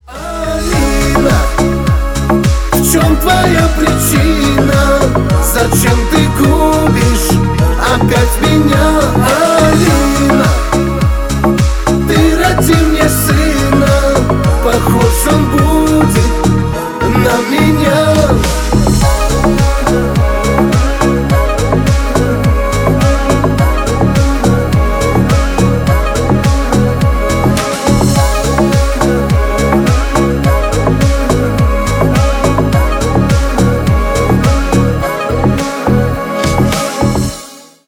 • Качество: 320 kbps, Stereo
Шансон